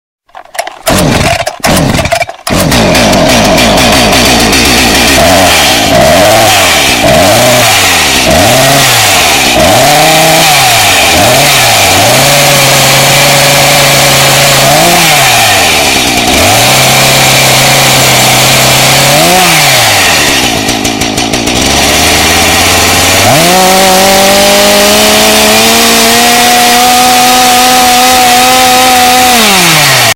Chainsaw Earrape Sound Effect Free Download
Chainsaw Earrape